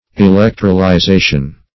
Electrolyzation \E*lec`tro*ly*za"tion\, n.